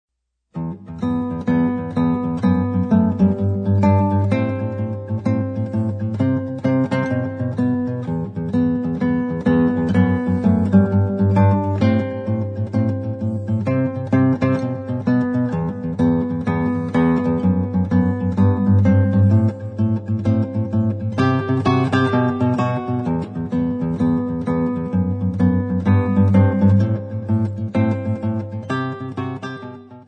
Noten & TAB - easy/medium
Besetzung: Gitarre